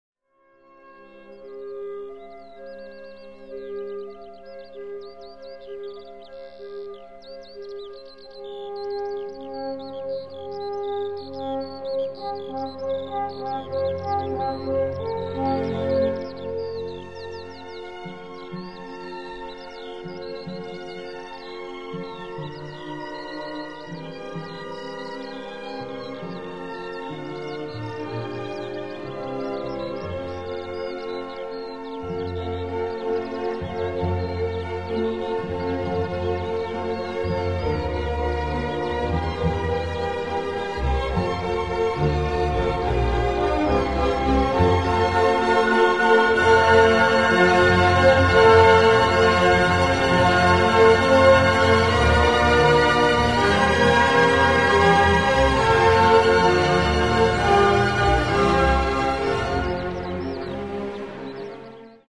Каталог -> Другое -> Relax-piano, музыкальная терапия
Mp3- V. Allegretto (звуки жаворонка и соловья)